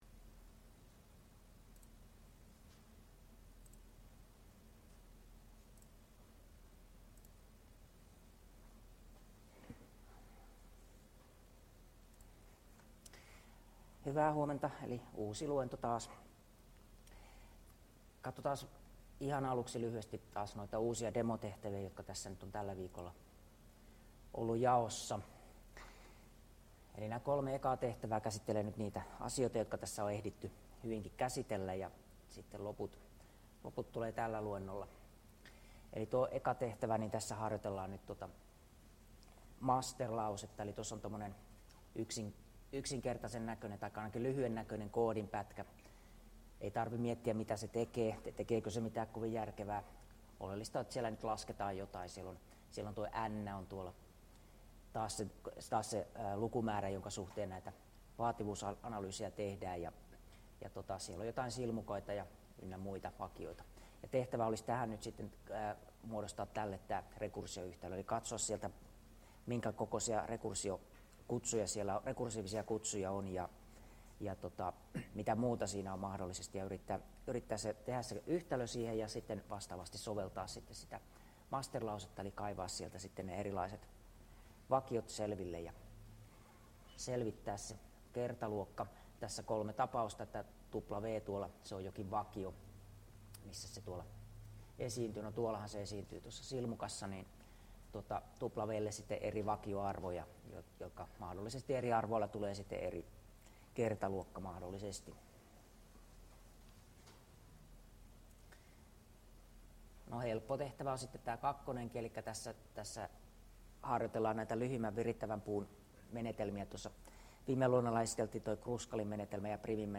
Luento 10 — Moniviestin